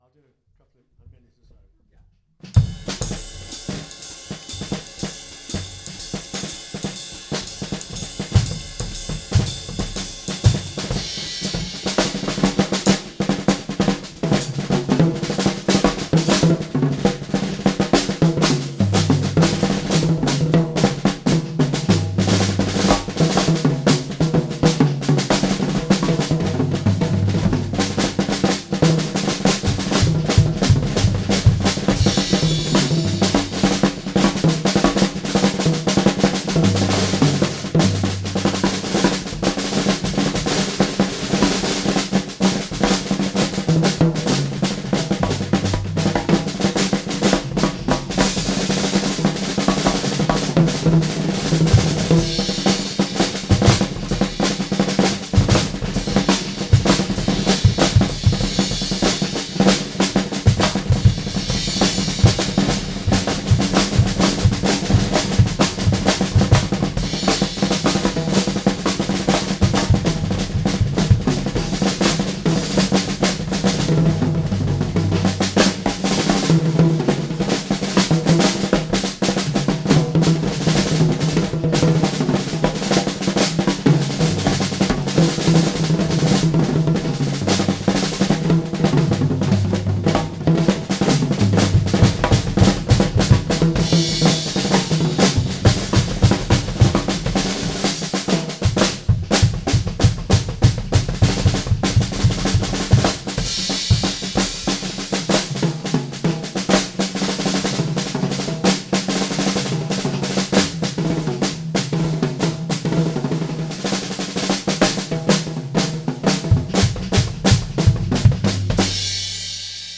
live jazz drummer
drum solo